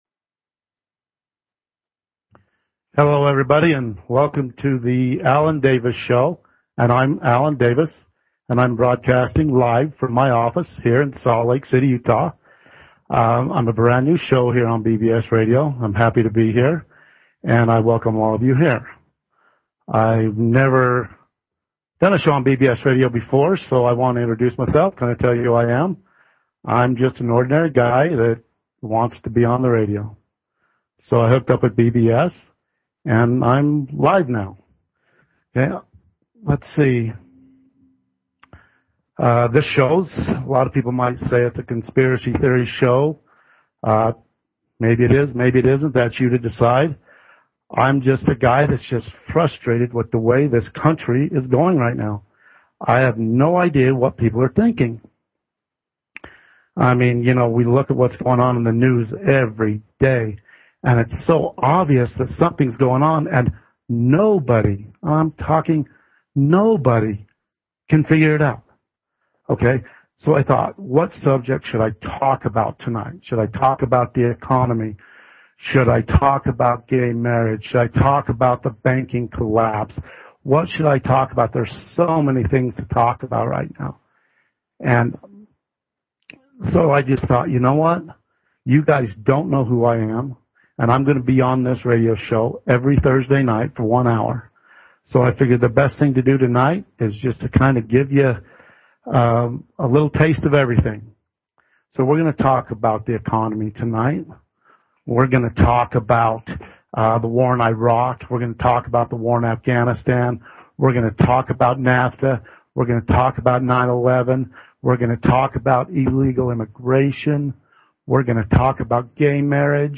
Talk Show
He encourages calls and emails from listeners.